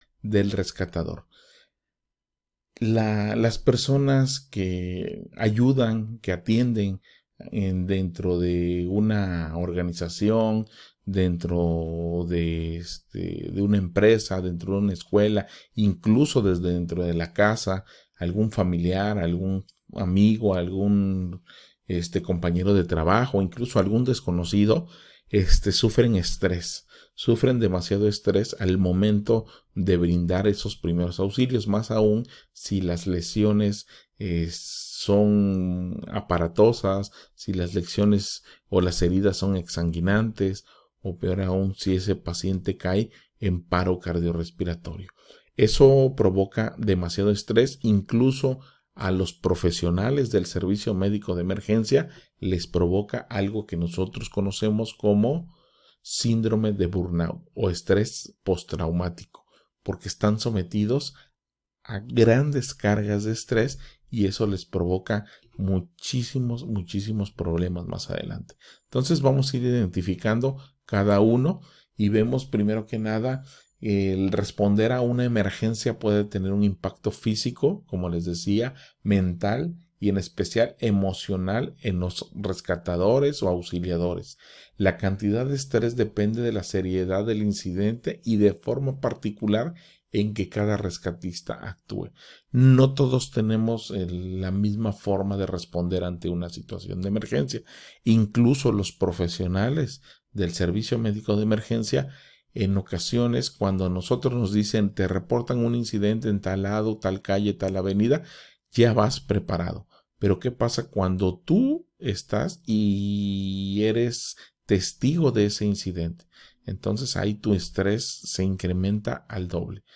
En este episodio, hablaremos con expertos en salud mental y rescatadores experimentados para discutir los signos comunes del estrés en los rescatadores, los efectos que puede tener en su salud, y las estrategias que pueden usar para manejarlo.